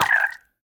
minecraft / sounds / mob / frog / death3.ogg
death3.ogg